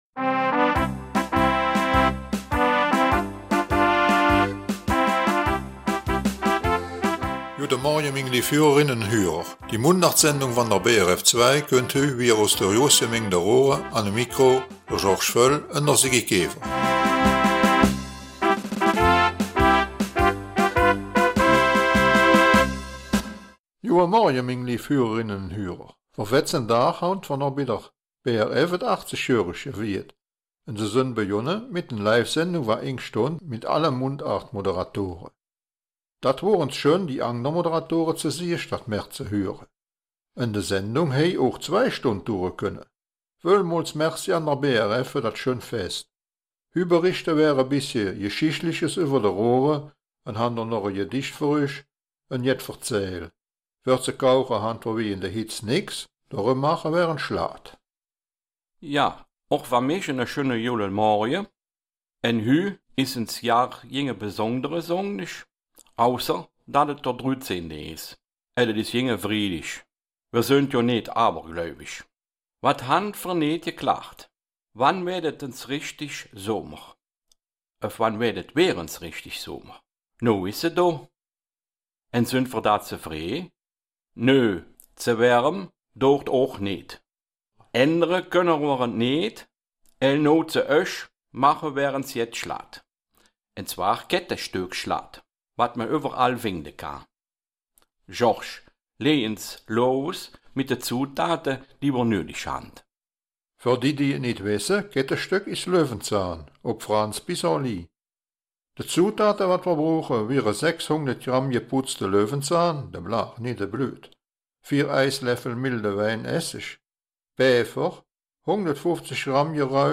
Raerener Mundart - 13. Juli
Die Mundartsendung vom 13. Juli aus Raeren bringt folgende Themen: